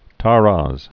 (täräz)